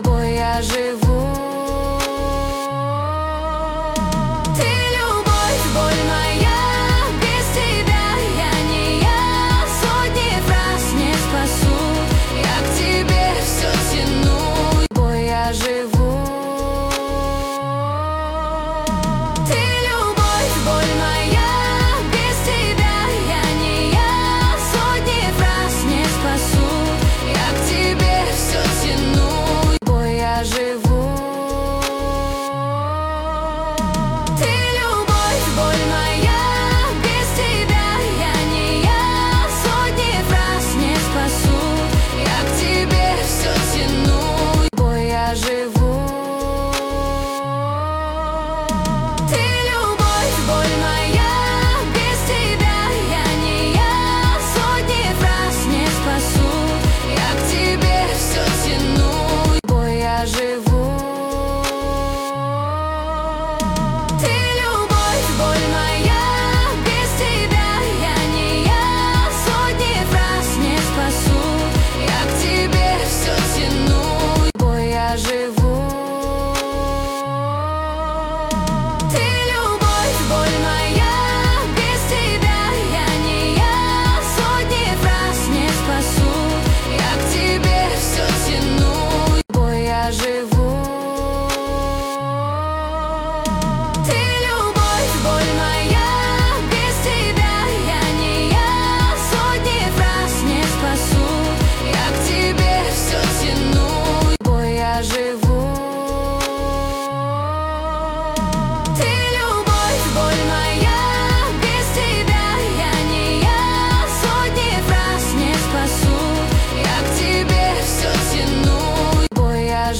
Рэп, Новинки